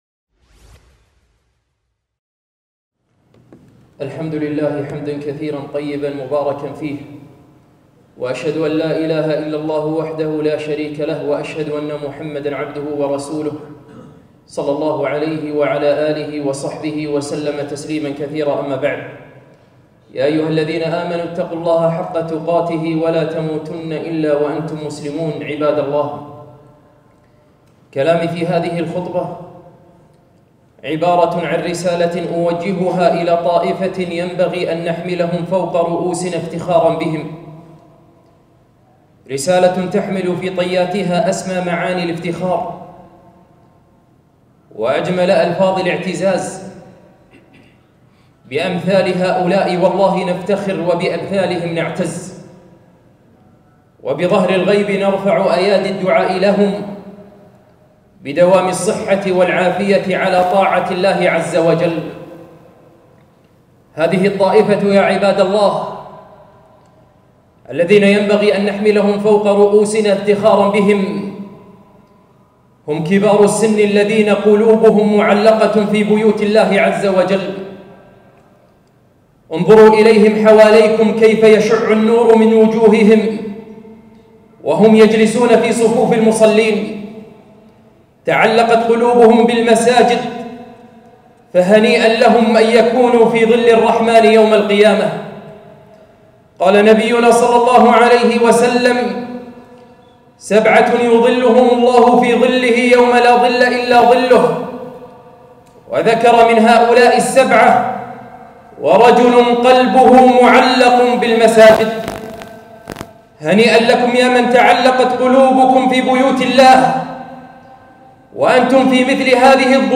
خطبة - طائفة نفتخر بها